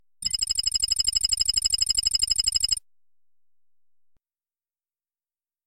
Звук печатания локации в фильме, например, военная база Мухосранск, Верджиния